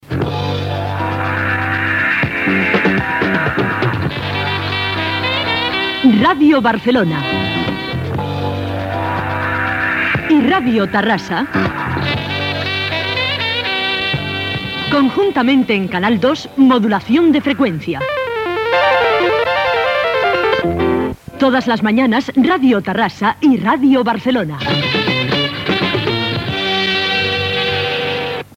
Indicatiu conjunt